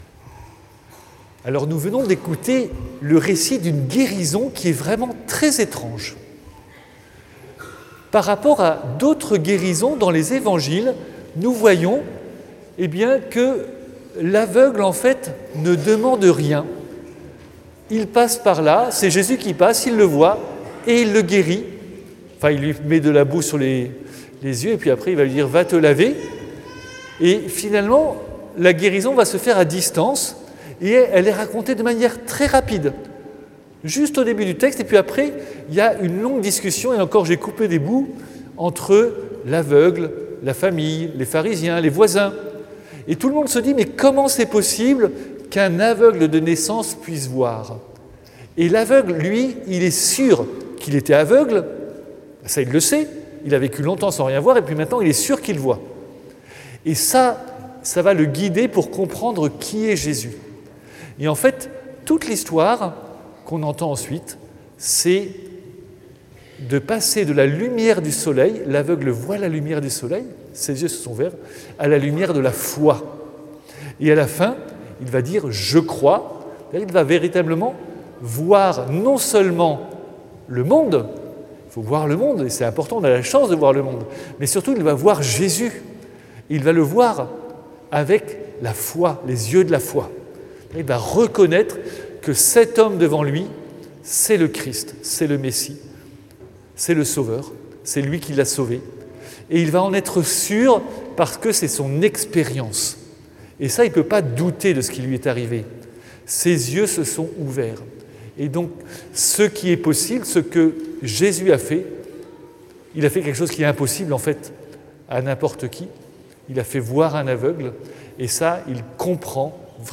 Audio : Homélie du 4ᵉ dimanche de Carême 2026